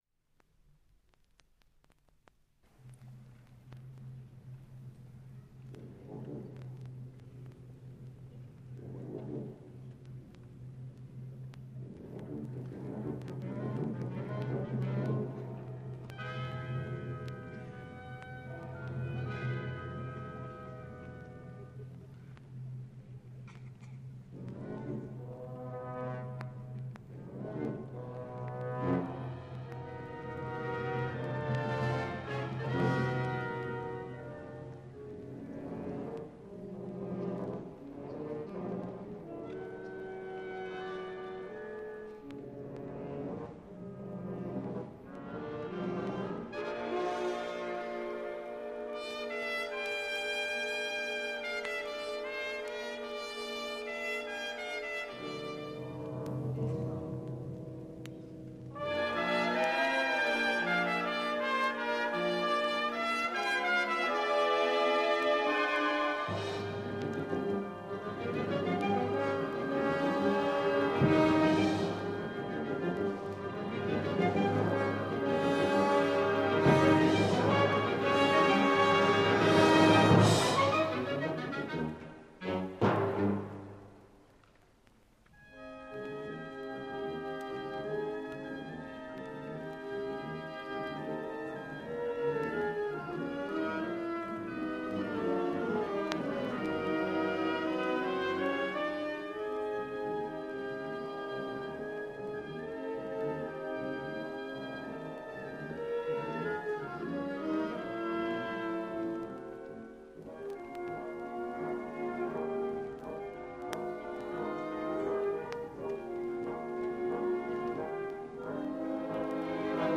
１９７０年代の高等部吹奏楽団の演奏会記録です
玉川学園高等部吹奏楽団 第９回定期演奏会
1976年1月25日(日) / 日比谷公会堂